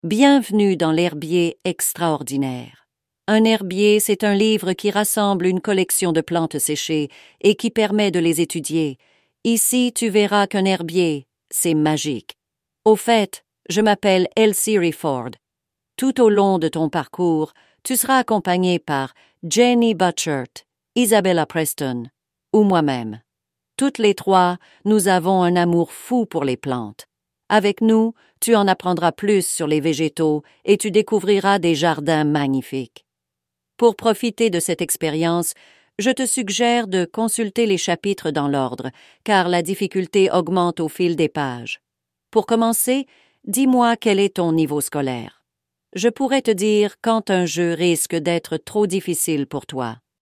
Narration de la botaniste